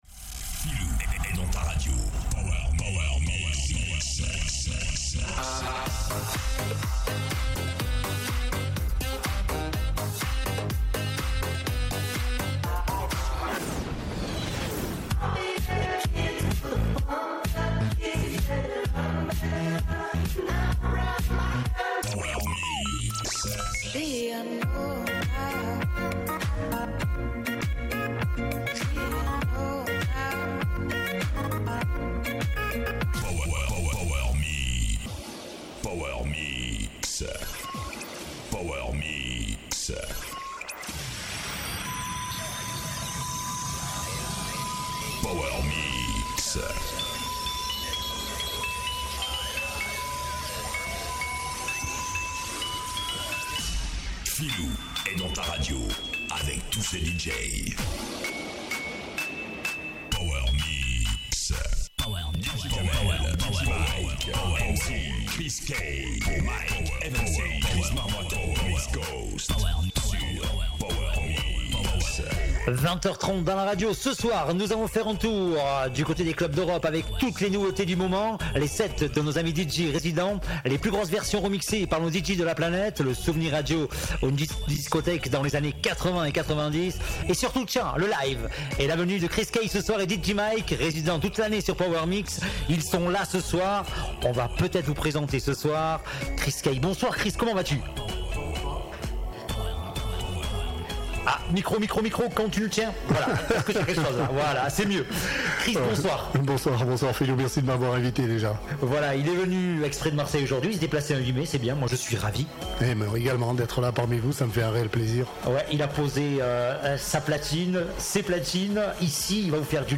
de retour sur Fréquence Mistral Sisteron pour POWER MIX c'est toute une nouvelle saison pour de nombreuses aventures...C'est le tour du monde des Night Club's !!!!! Les sorties de la semaine, les souvenirs impérissables...